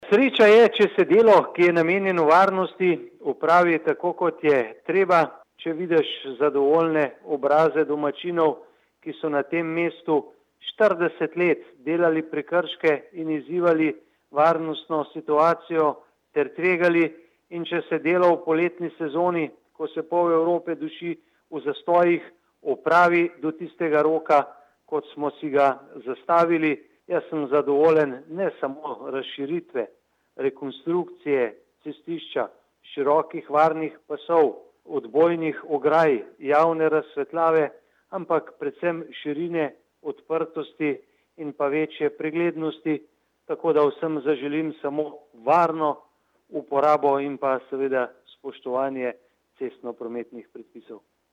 92054_izjava_zupanobcinetrzicmag.borutsajovicokriziscuvpodljubelju.mp3